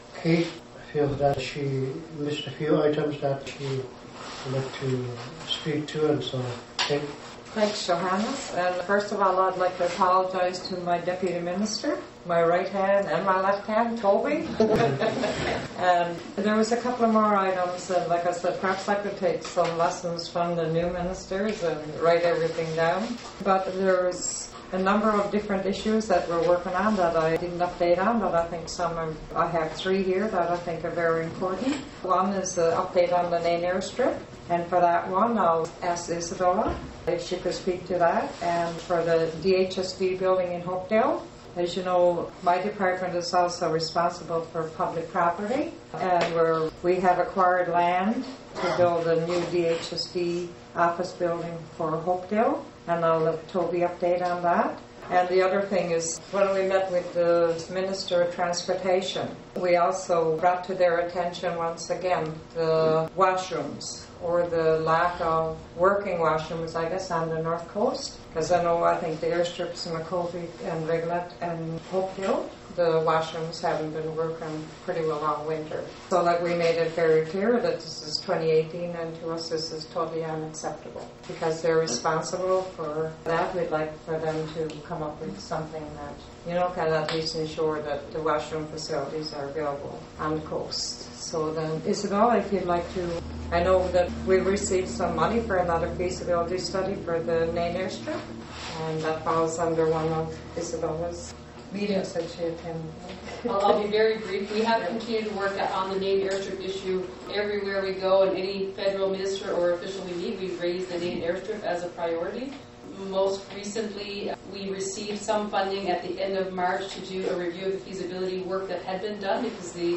The Nunatsiavut Executive Council (NEC) had their Open House last Monday evening at the Nunatsiavut Government building boardroom at 7 pm.
President Johannes Lampe started off the evening by welcoming everyone.
Then, each minister reported on their departments.